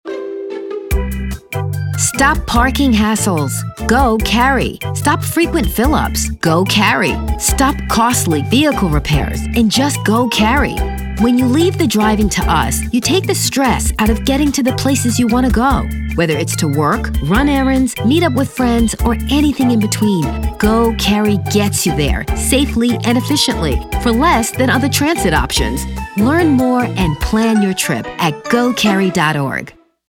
Friendly, Smart and Engaging.
Standard American, New York (Long Island)
Young Adult
Middle Aged
Commercial